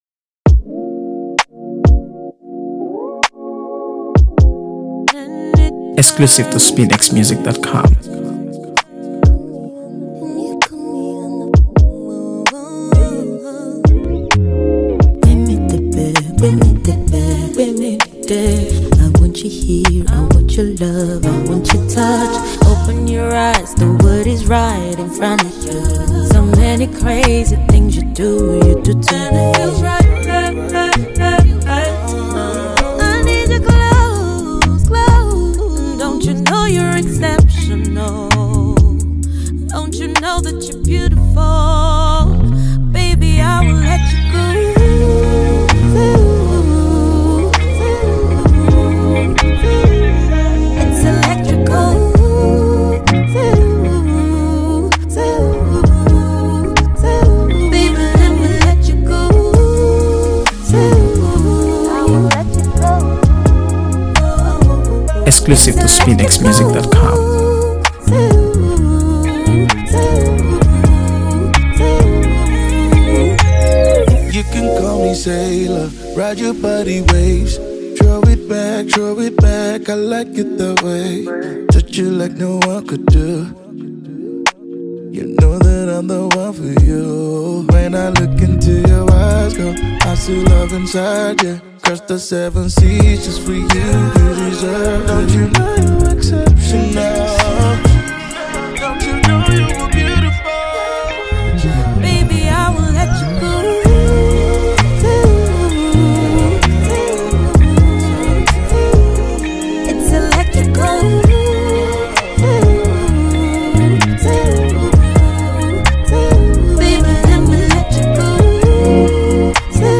Afro-house
With its infectious melody and captivating energy